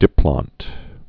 (dĭplŏnt)